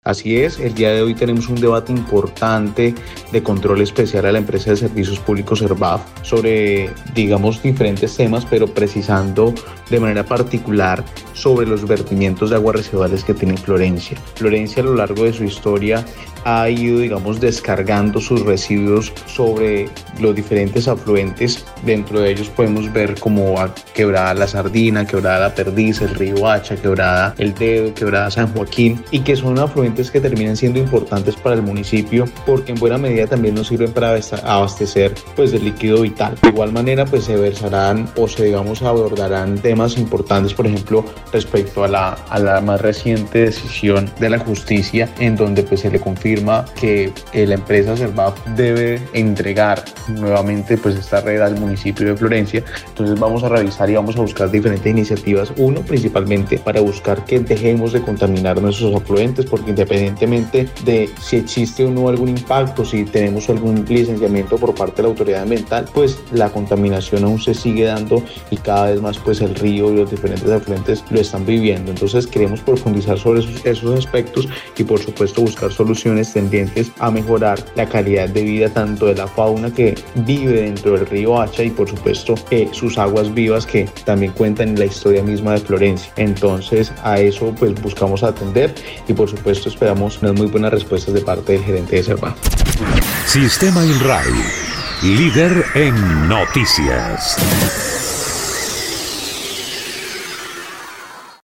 Erick Sánchez, concejal por el Partido Alianza Verde, explicó que, afluentes hídricos como las quebradas El Dedo, San Juaquín, La Sardina, La Perdiz y el río Hacha, han sido históricamente afectadas por el vertimiento de este tipo de aguas residuales, afectando el medioambiente.
04_CONCEJAL_ERICK_SANCHEZ_SERVAF.mp3